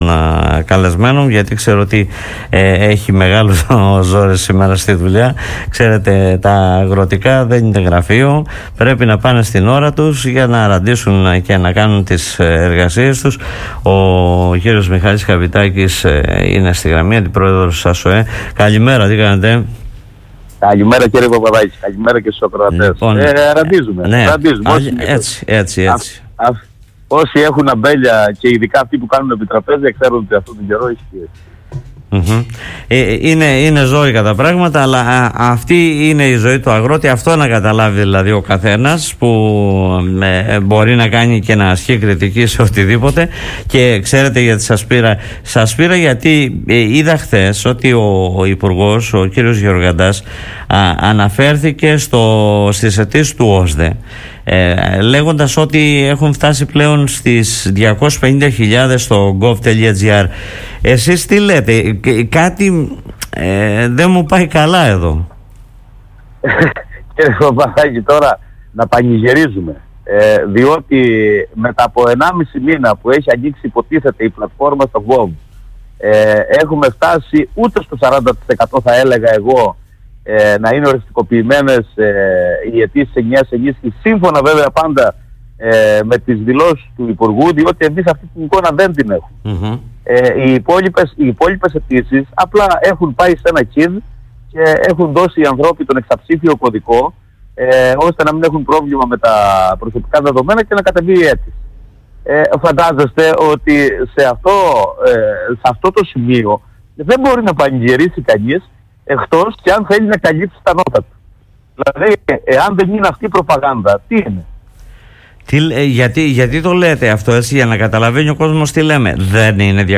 μιλώντας στον politica 89.8